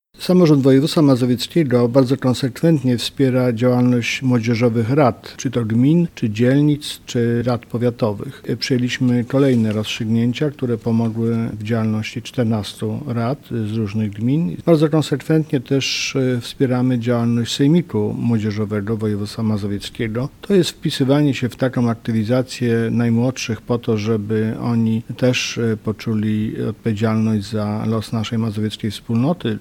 W tym roku samorząd województwa mazowieckiego zrealizuje 48 projektów młodzieżowych rad z całego regionu. Na ten cel przeznaczono blisko 900 tys. złotych- mówi marszałek województwa mazowieckiego, Adam Struzik.